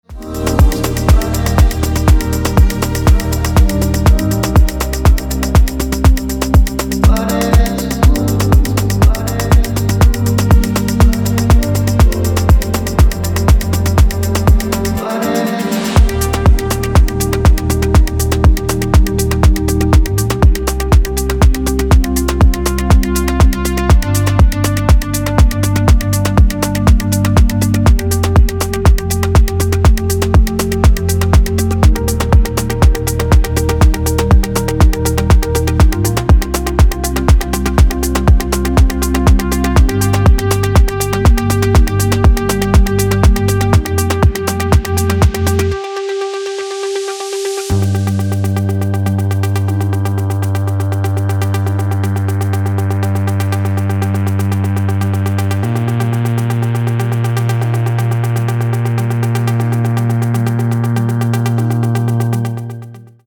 Progressive House / Organic House